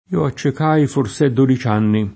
forse [f1rSe] avv. — elis. (antiq. in genere, ancóra di norma in fors’anche): io accecai fors’è dodici anni [